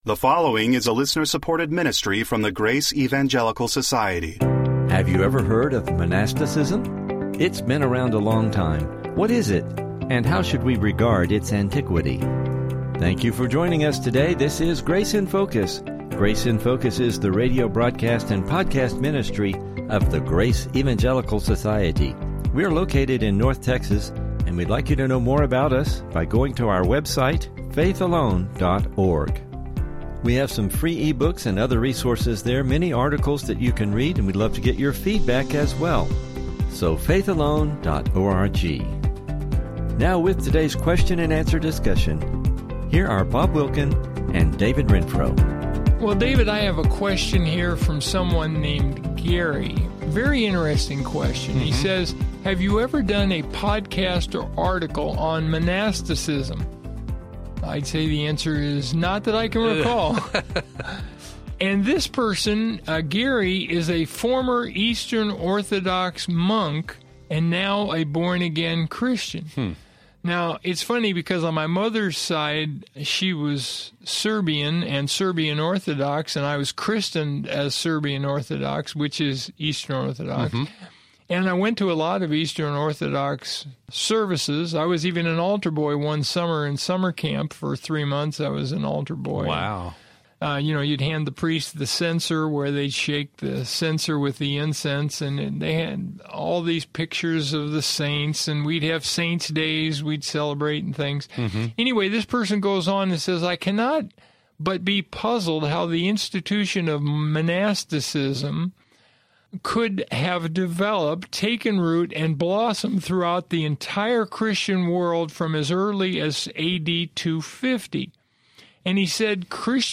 Welcome to the Grace in Focus radio.